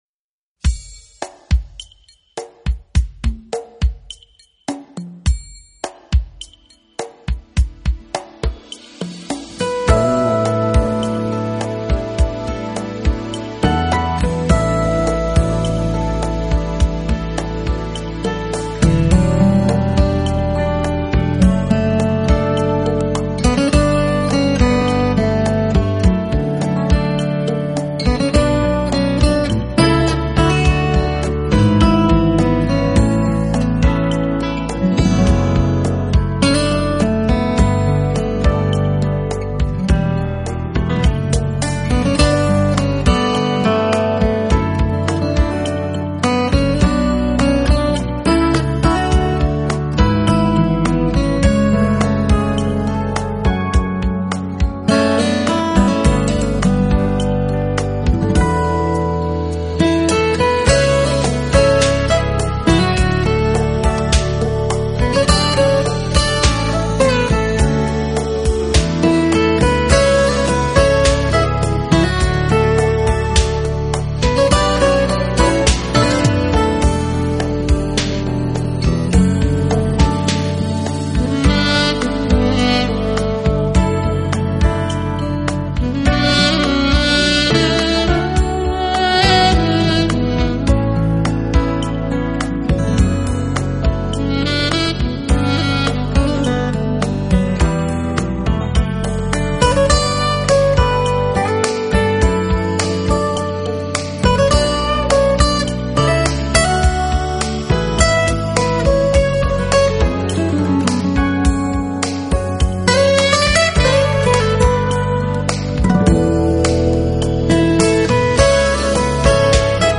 【纯音乐】世界器乐精选集VA